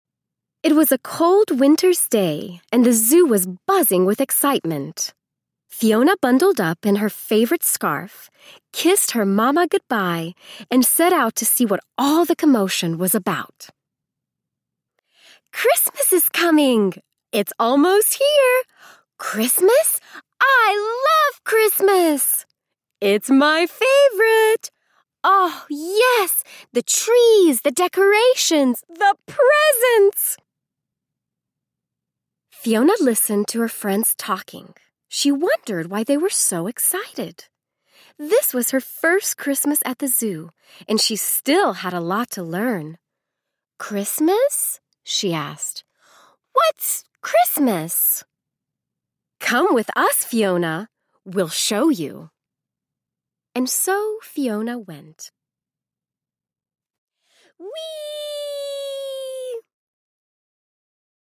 A Very Fiona Christmas Audiobook
Narrator
0.1 Hrs. – Unabridged